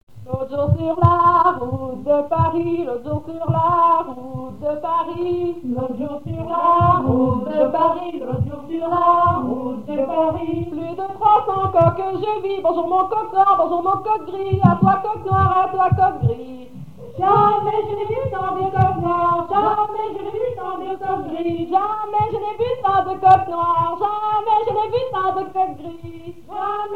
Genre laisse
chansons à danser ronds et demi-ronds
Pièce musicale inédite